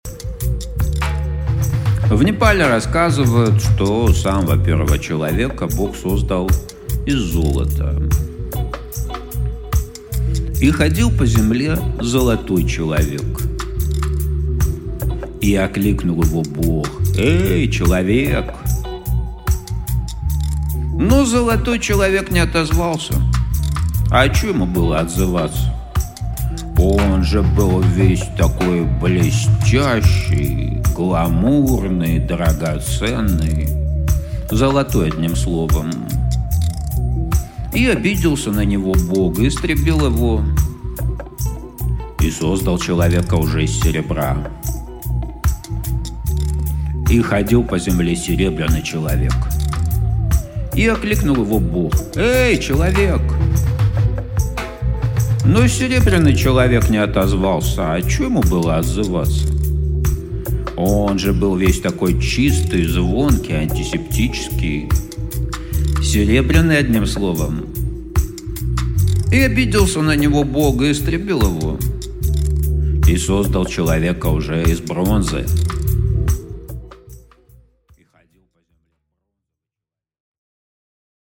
Aудиокнига Сотворение человека Автор Дмитрий Гайдук.